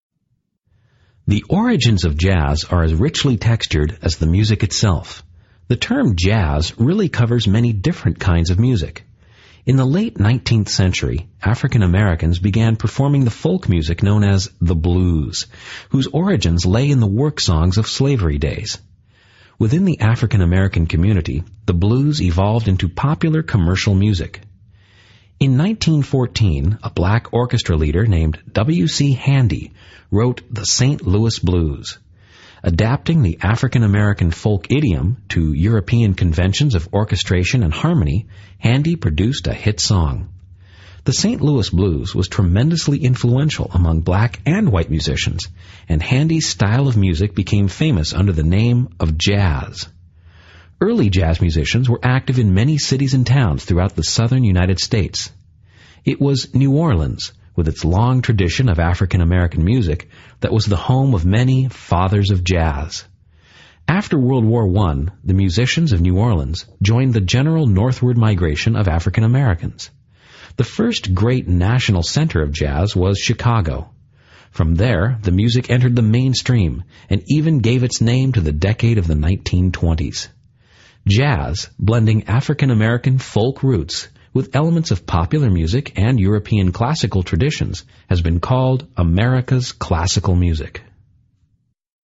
Q. What does an instructor imply about the style of music known as the blues?